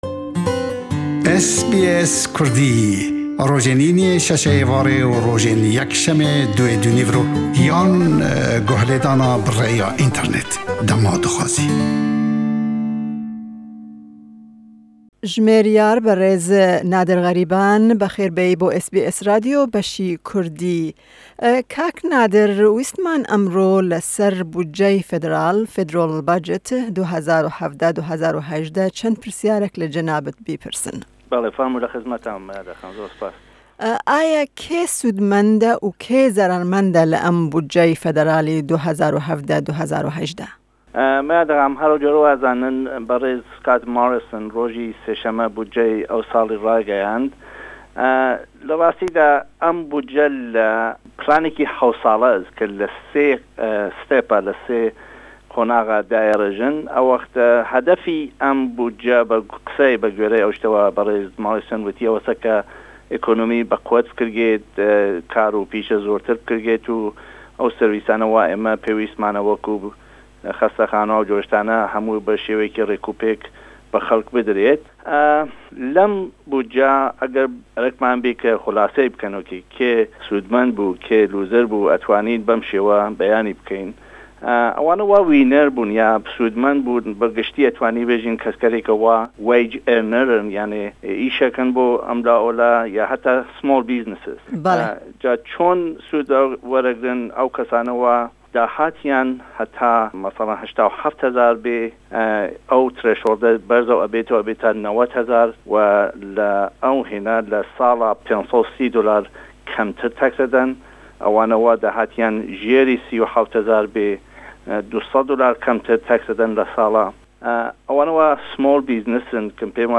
Me di vê hevpeyvînê de pirsên derbarê buceya 2018/2019